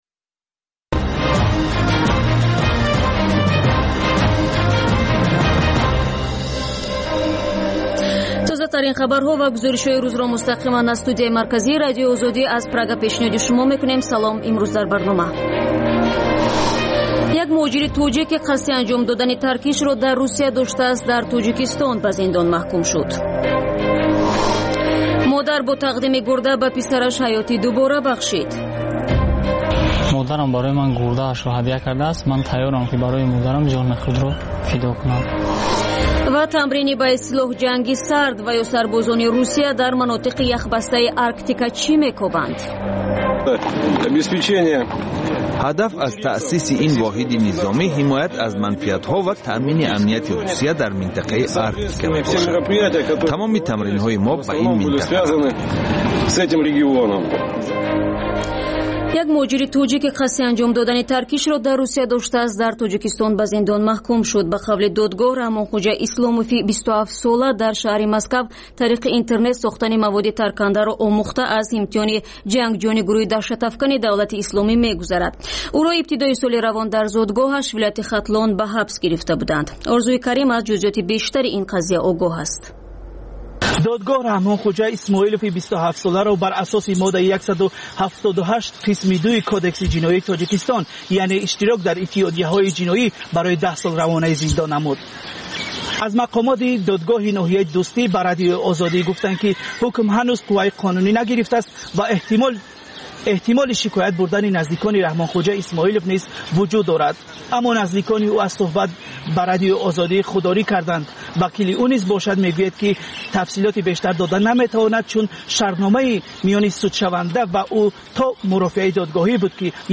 Тозатарин ахбор ва гузоришҳои марбут ба Тоҷикистон, минтақа ва ҷаҳон дар маҷаллаи шомгоҳии Радиои Озодӣ